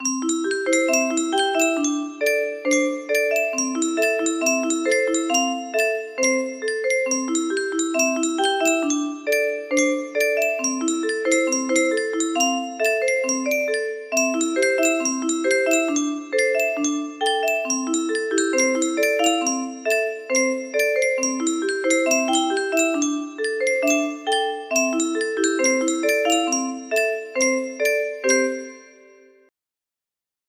Chorus part